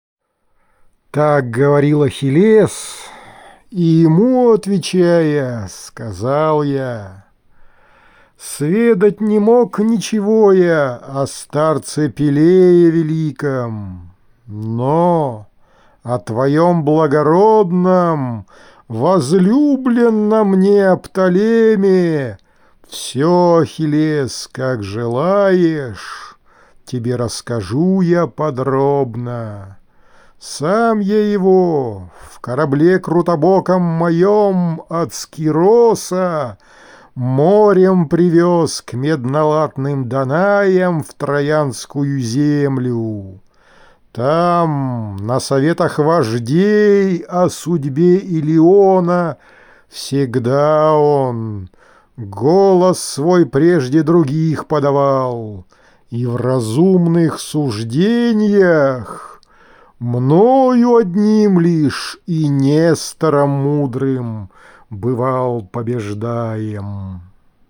Одиссея. Песня одиннадцатая: Декламация